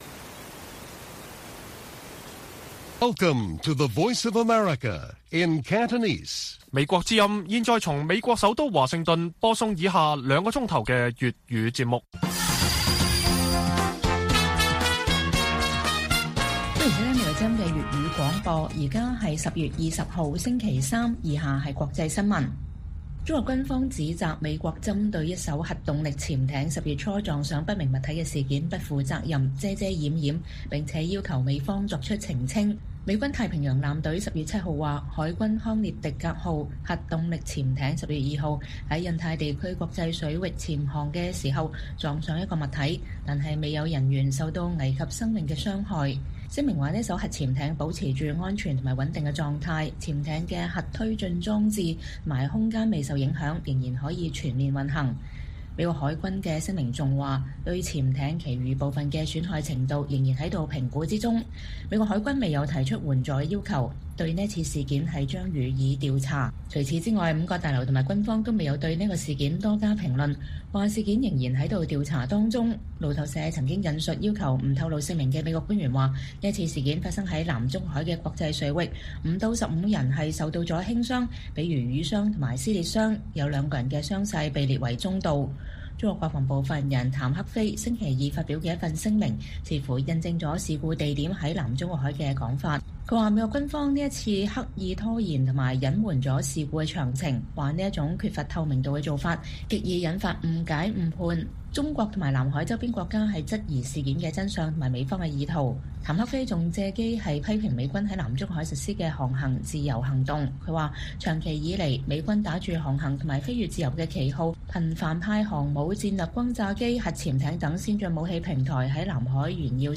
粵語新聞 晚上9-10點: 不透明的中國軍方指責美國在核潛艇事件上“遮遮掩掩”